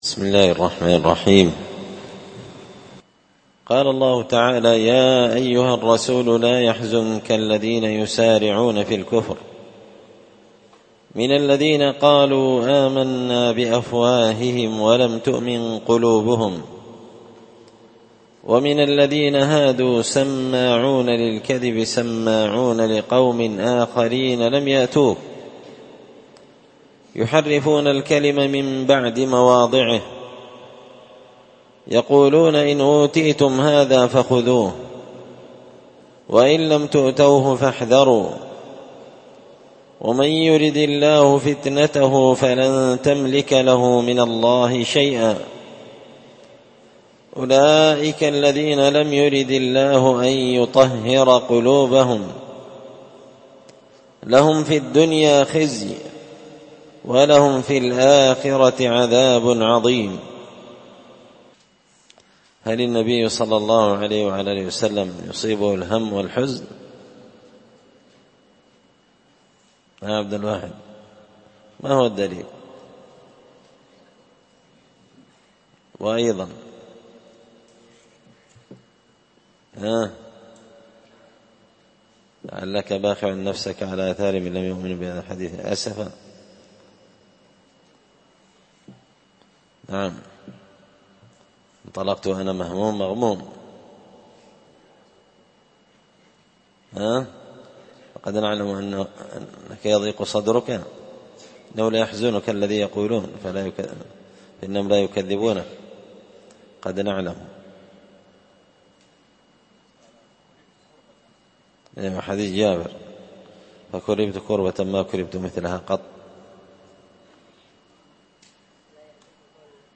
مختصر تفسير الإمام البغوي رحمه الله الدرس 253